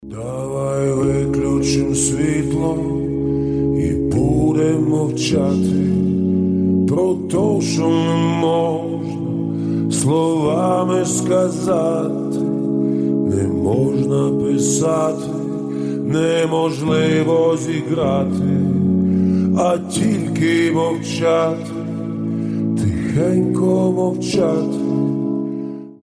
• Качество: 320, Stereo
спокойные
нежные